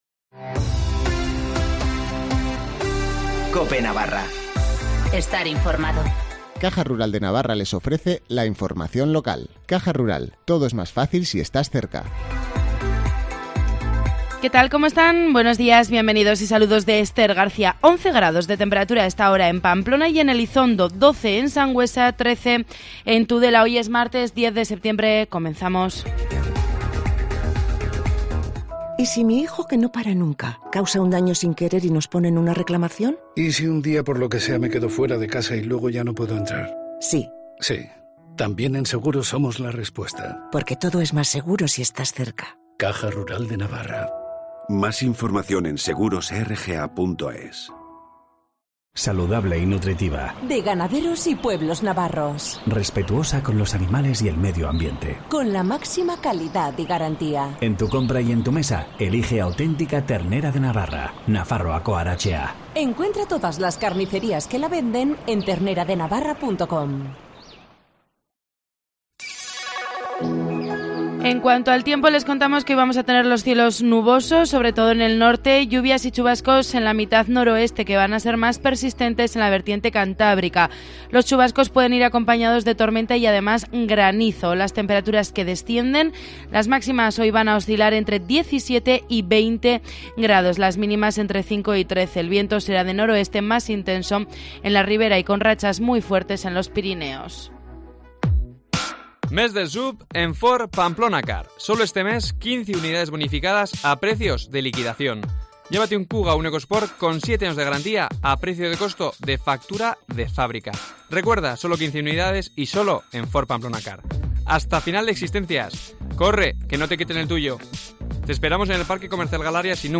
Informativo matinal del 10 de septiembre